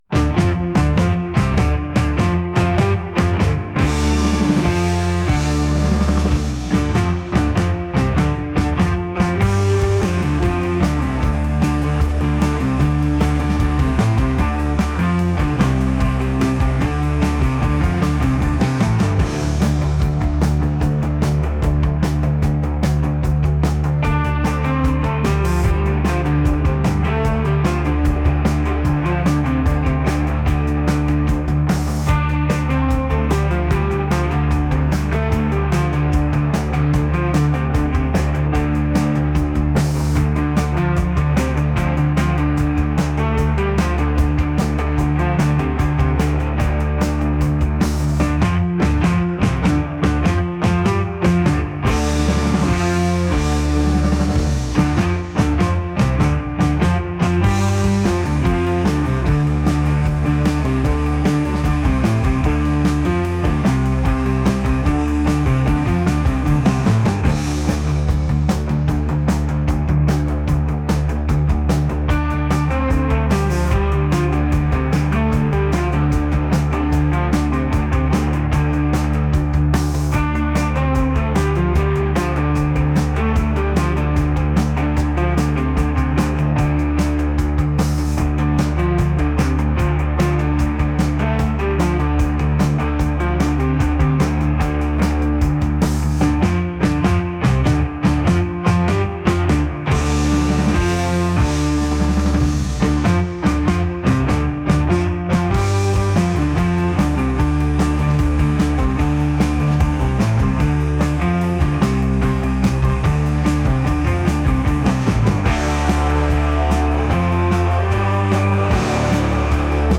rock | retro